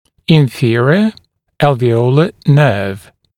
[ɪn’fɪərɪə ˌælvɪ’əulə nɜːv] [ˌæl’viːələ][ин’фиэриэ ˌэлви’оулэ нё:в] [ˌэл’ви:элэ]нижний альвеолярный нерв